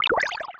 Water10.wav